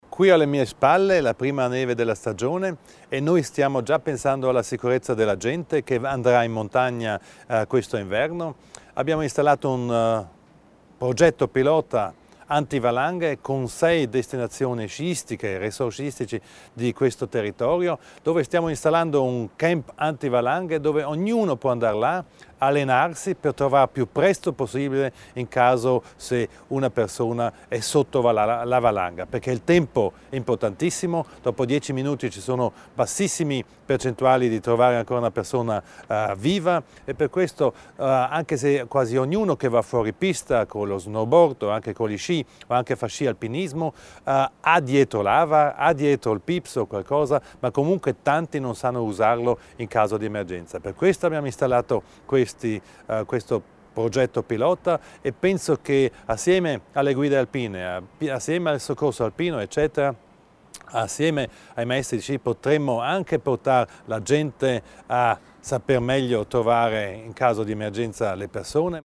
L'Assessore Widmann spiega l'importanza del progetto dedicata alla sicurezza in montagna